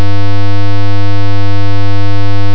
Square Wave   Lab 3, due February 11.
squarewave1.wav